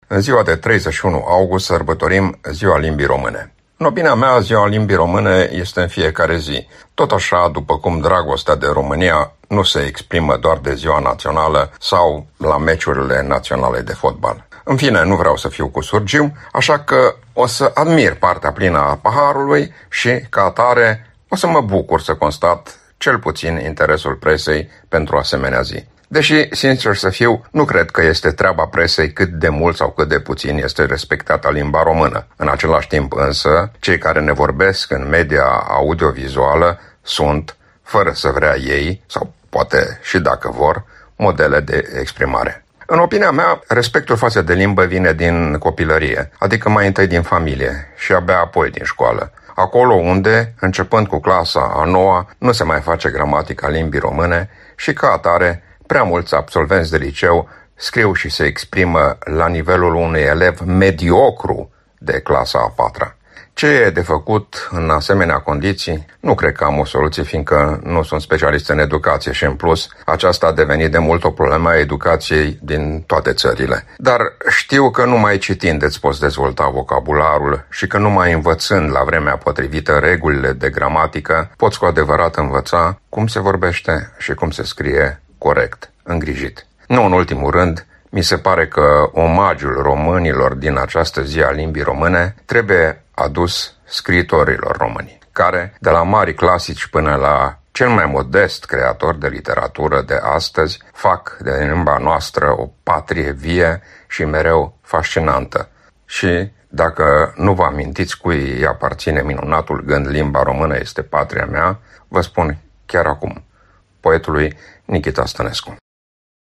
Acest comentariu despre Ziua Limbi Românepoate fi considerat ca o ediție specială a rubricii și este transmis astăzi , 31 august 2025.